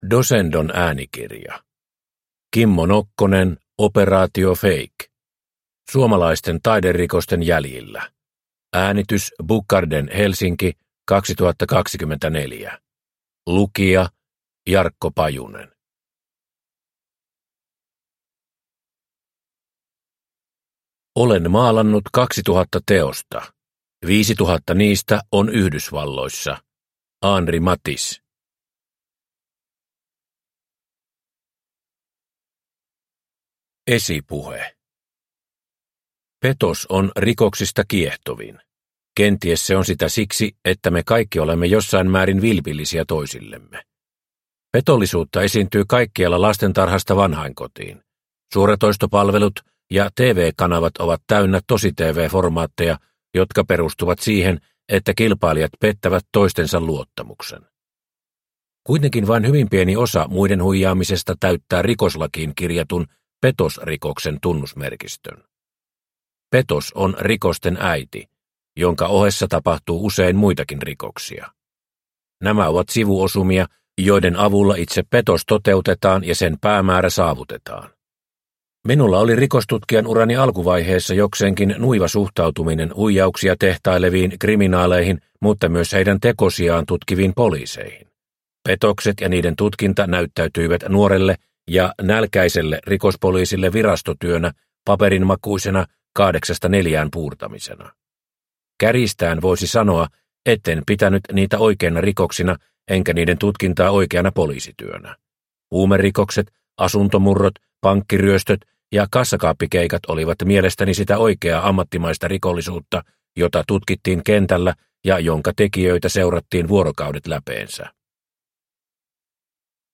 Operaatio Fake – Ljudbok